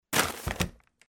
trashplastic4.mp3